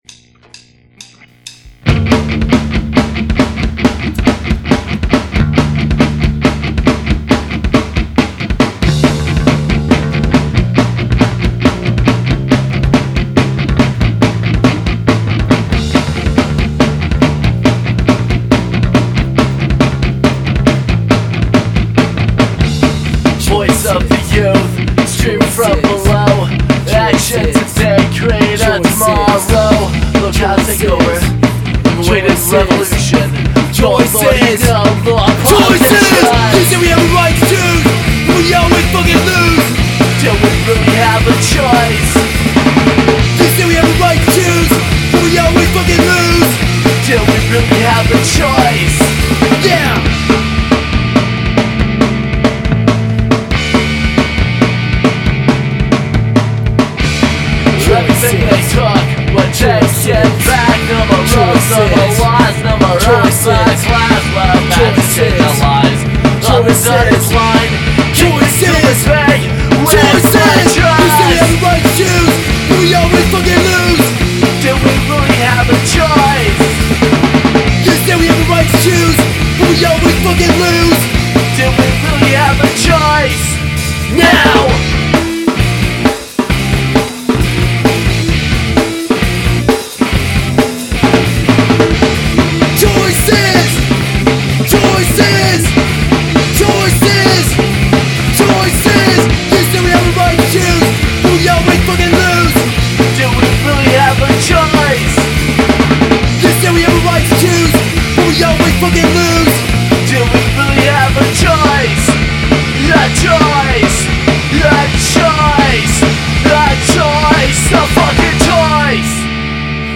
Here is an mp3 that we basically recorded live in a farm shed with a four track recorder.